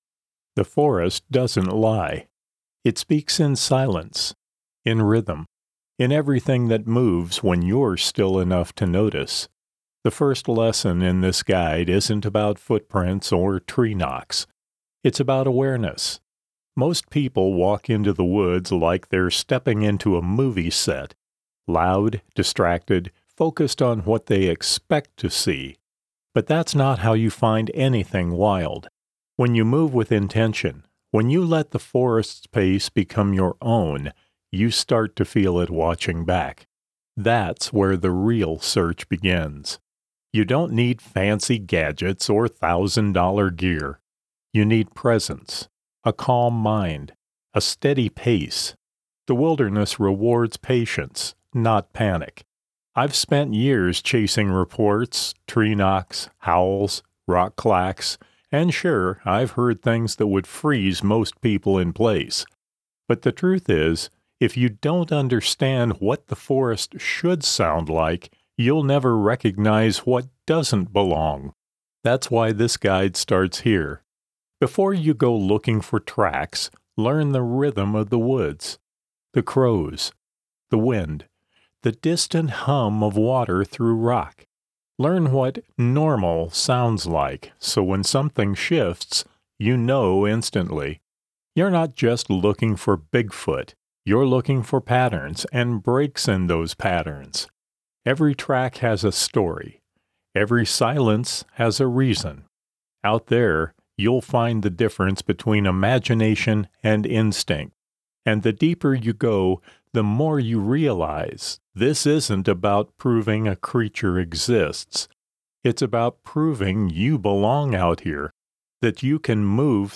Audiobook narration
Middle Aged